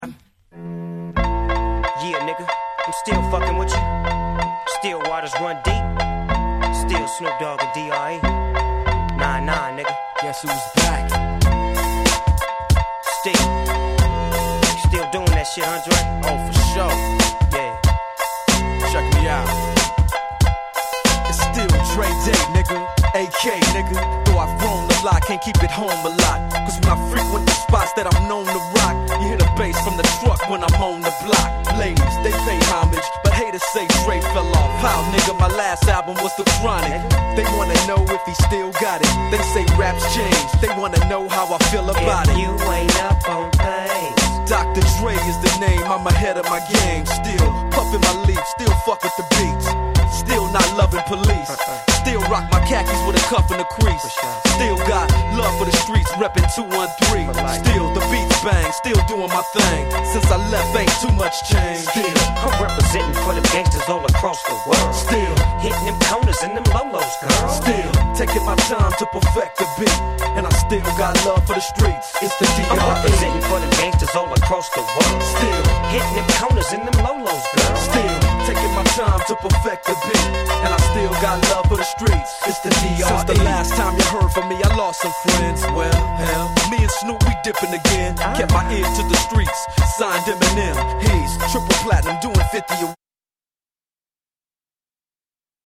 G-Rap Gangsta Rap